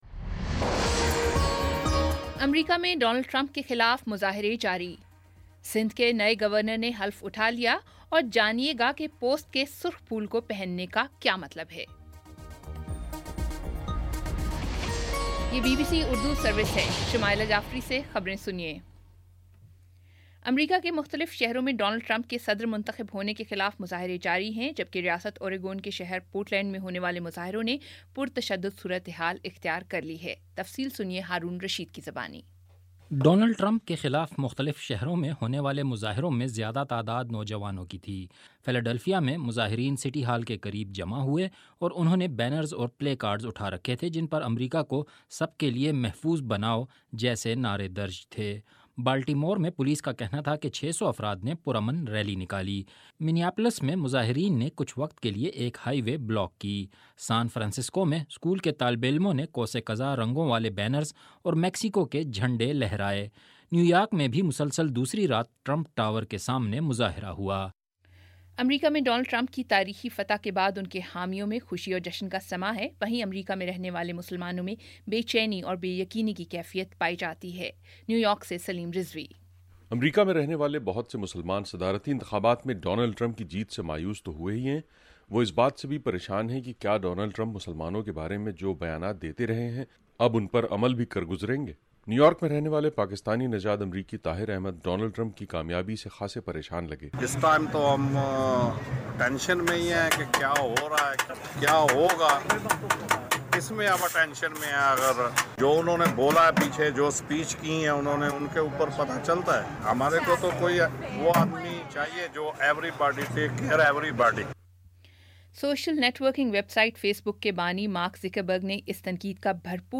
نومبر 11 : شام پانچ بجے کا نیوز بُلیٹن